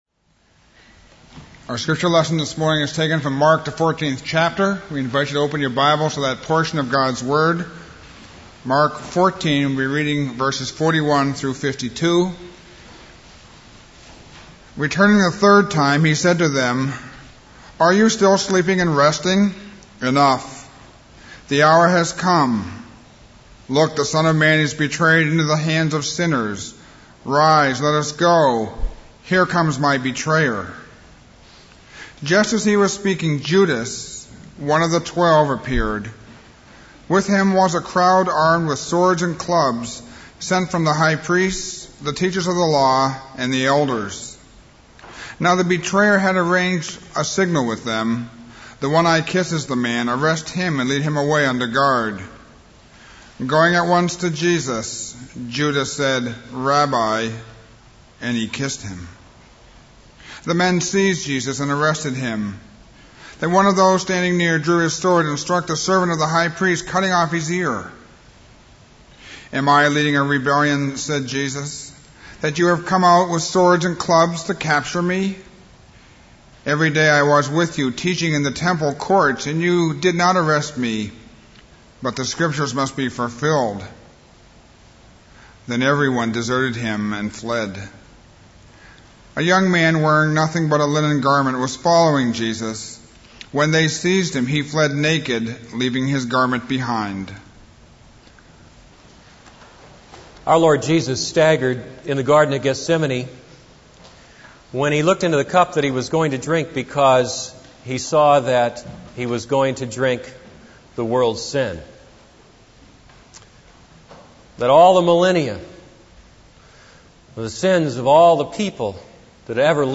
This is a sermon on Mark 14:41-52.